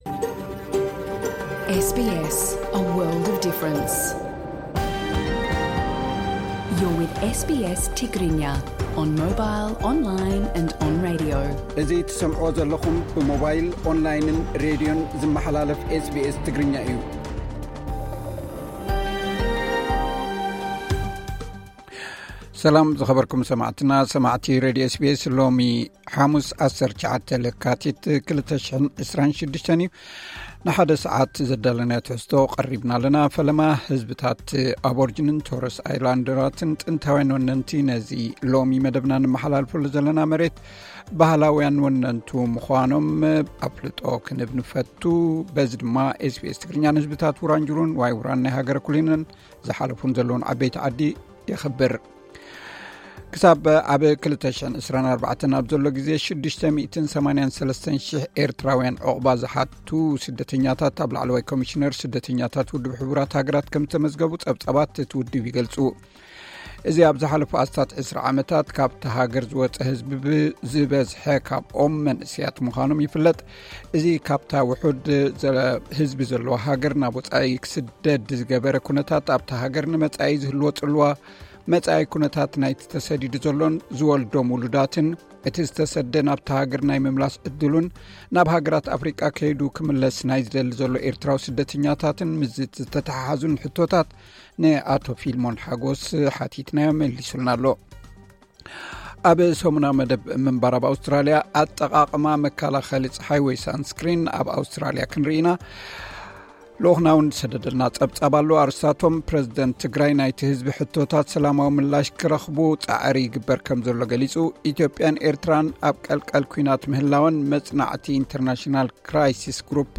ቀጥታ ምሉእ ትሕዝቶ ኤስ ቢ ኤስ ትግርኛ (19 ለካቲት 2026)